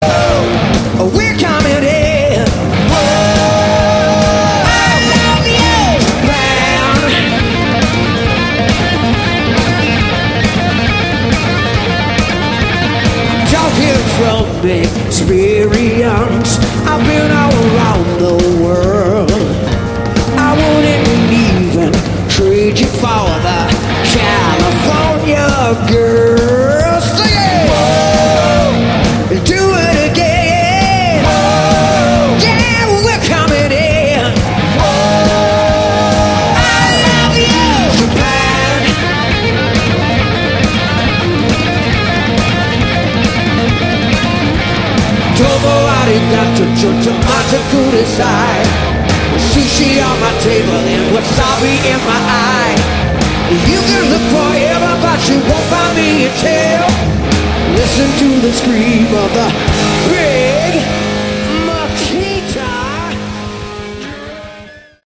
Category: Hard Rock
Bass
vocals
Drums
Guitars